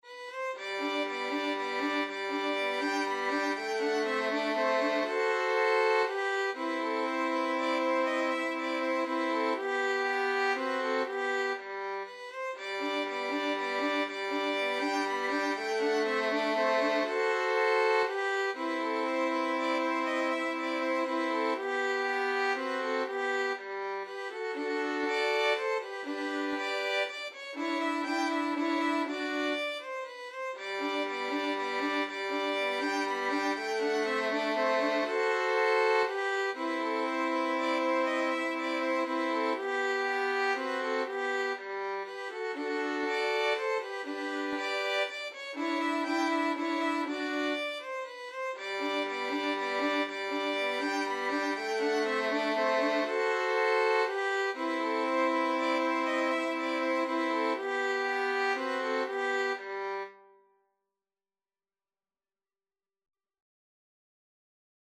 Free Sheet music for Violin Trio
Violin 1Violin 2Violin 3
3/4 (View more 3/4 Music)
G major (Sounding Pitch) (View more G major Music for Violin Trio )
Violin Trio  (View more Easy Violin Trio Music)
Classical (View more Classical Violin Trio Music)